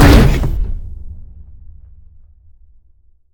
droidic sounds
hurt2.ogg